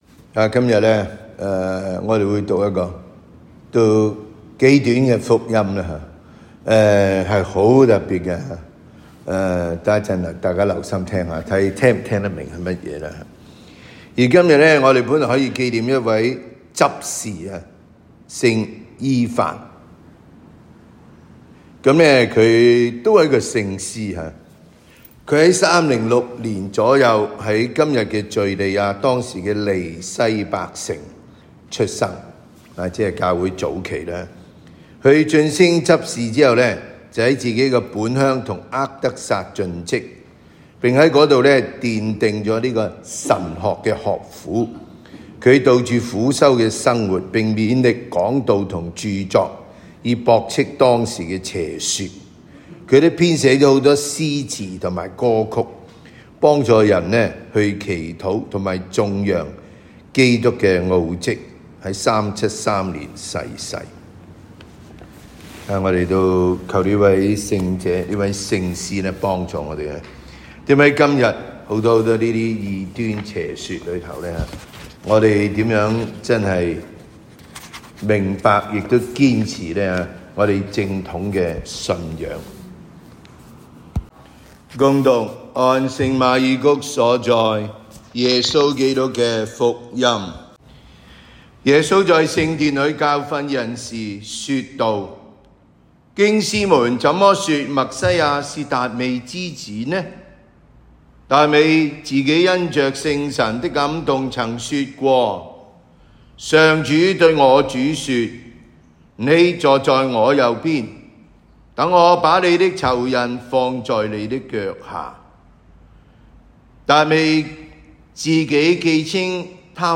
每日講道及靈修講座